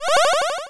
spring.wav